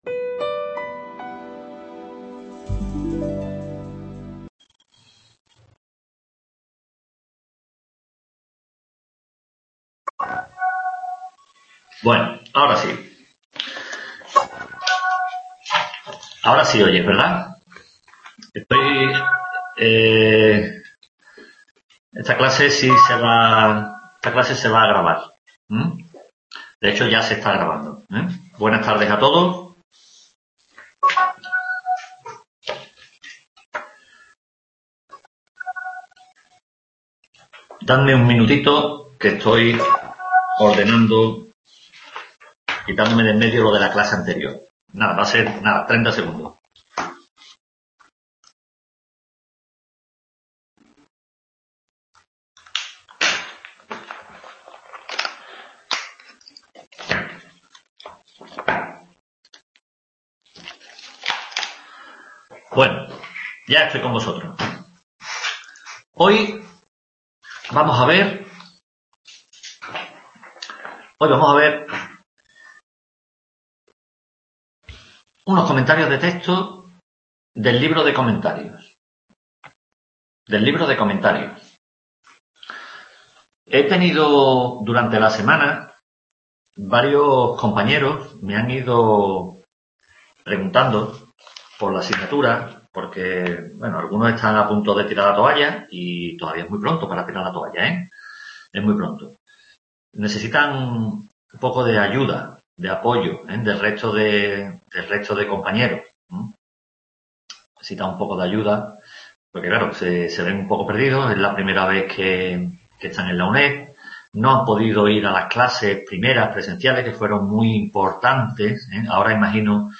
SEXTA TUTORÍA DE HISTORIA DEL DERECHO